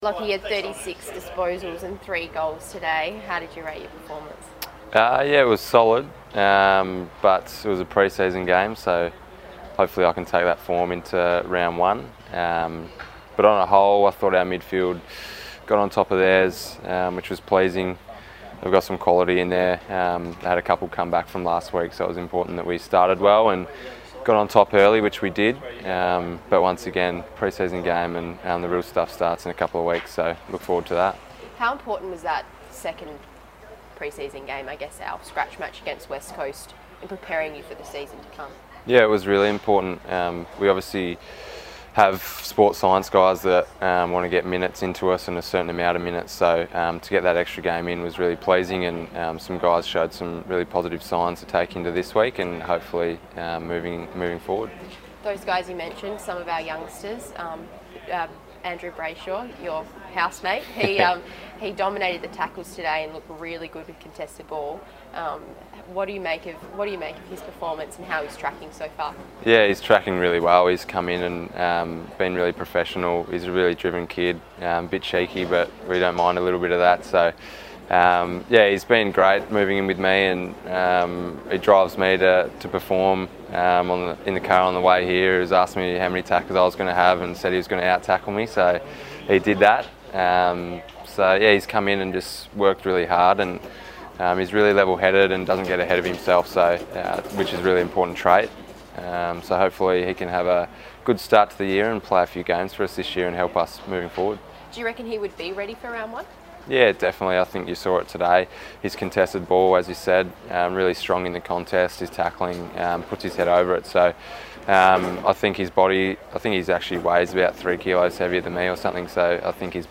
Lachie Neale chats to Docker TV after Freo's JLT clash against the Eagles.